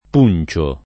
puncio [ p 2 n © o ]